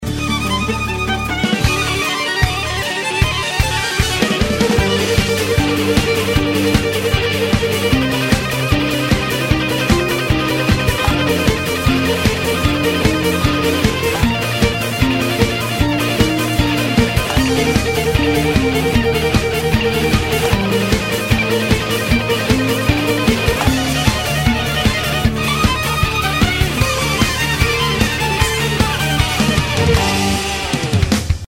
[АПНУТО 14.12.2011] Съем соло (Гитара+скрипка)
Всем привет! снимаю соляк гитары, чето не могу нормально слух от скрипки отделить, пробовал в аудишне Central channel exctract'...